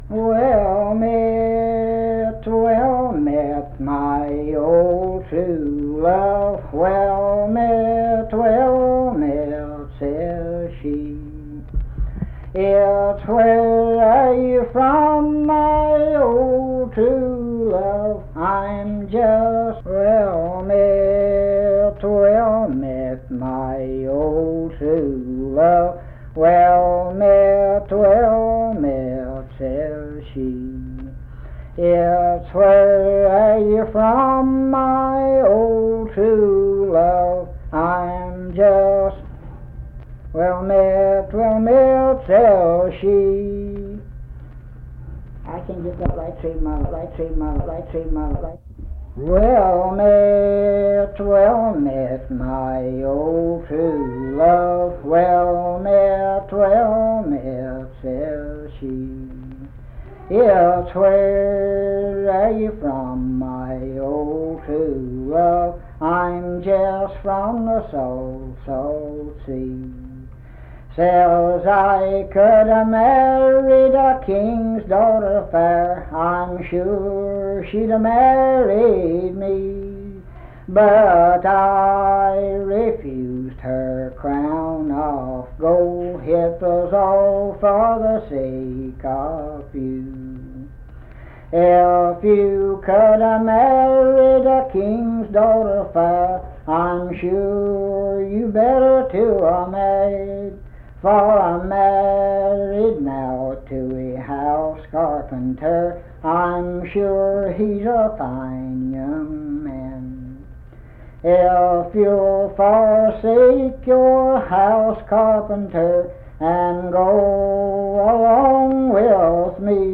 Unaccompanied vocal music
Performed in Naoma, Raleigh County, WV.
Voice (sung)